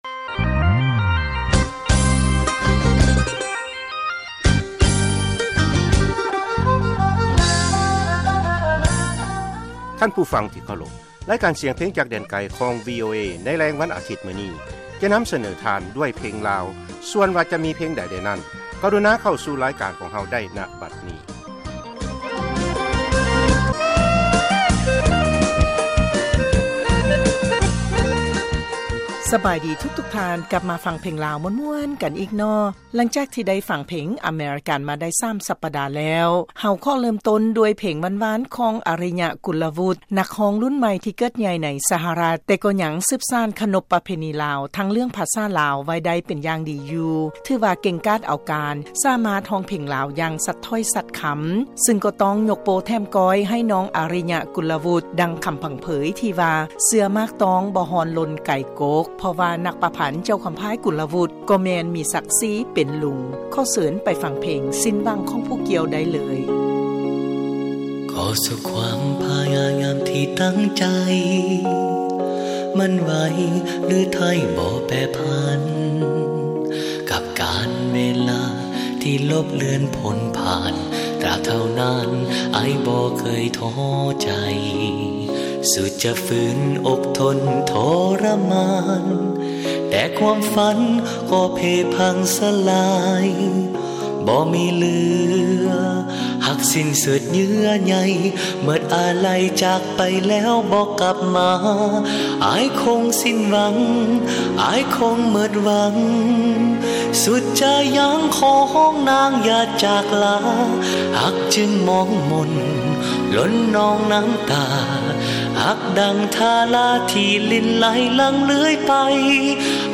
ເຮົາຈະສະເໜີເພງລາວທັງມ່ວນແລະໃໝ່